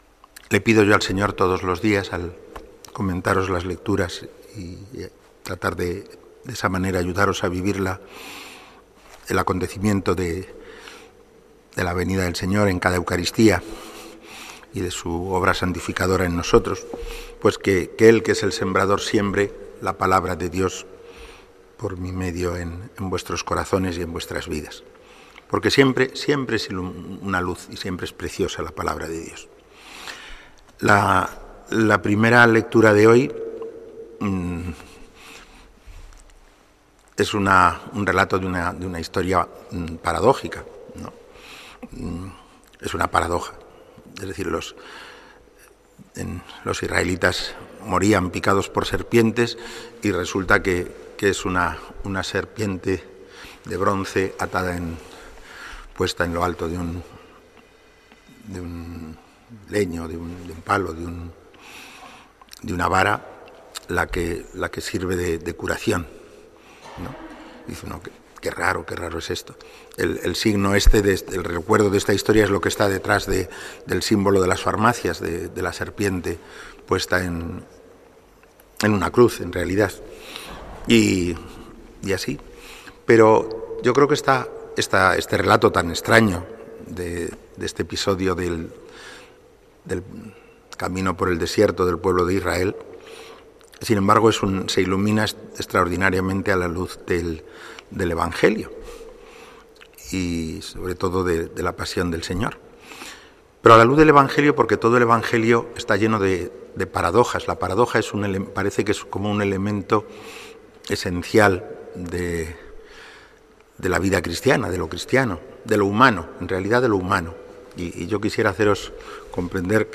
Iglesia parroquial Sagrario Catedral